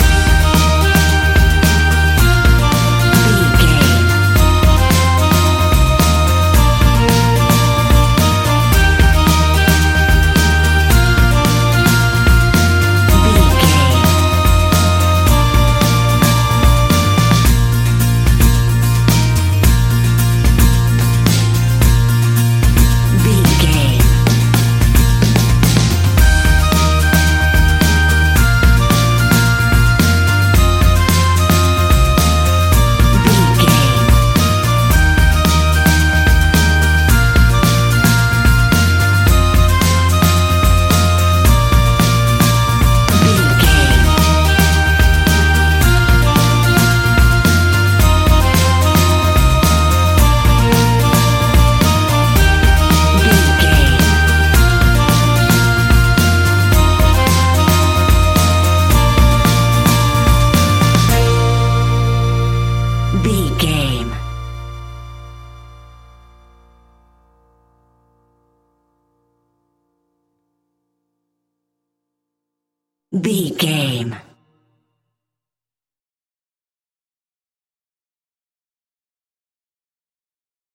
Uplifting
Ionian/Major
E♭
acoustic guitar
mandolin
double bass
accordion